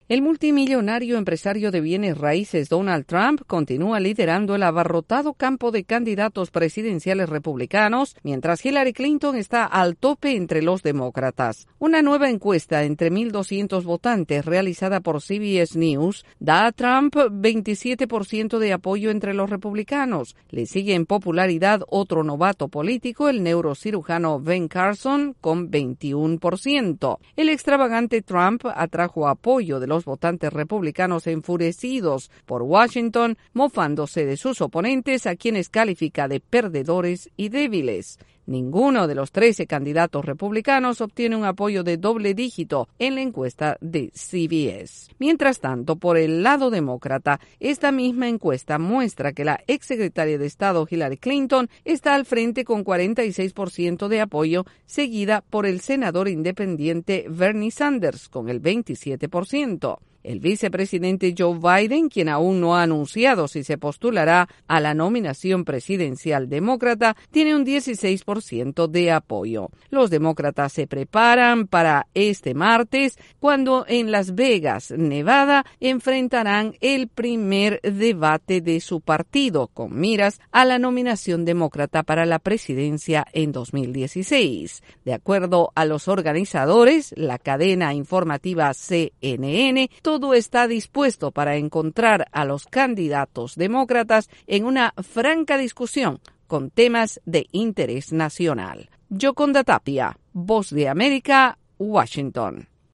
Demócratas y republicanos continúan en campaña política en Estados Unidos, con nuevas encuestas y el primer debate demócrata que se realiza el martes. Desde la Voz de América en Washington DC informa